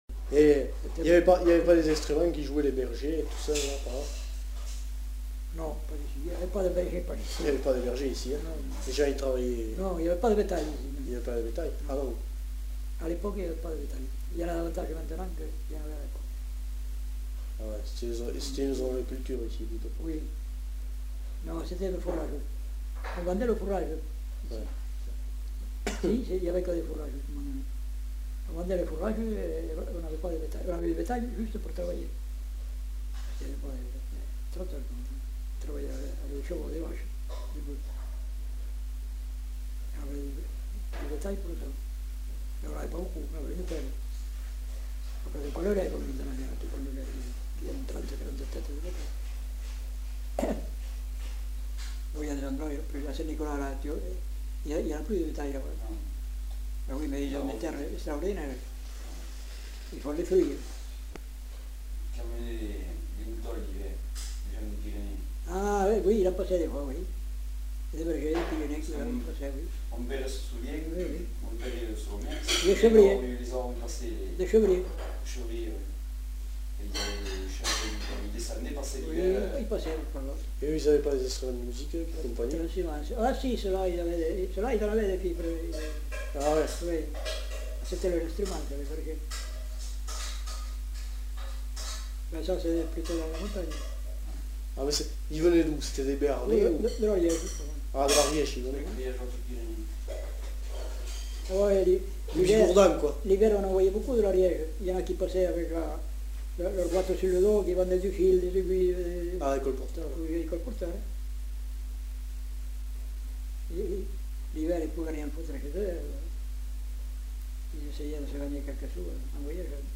Air fredonné qu'on jouait sur la flûte de chevrier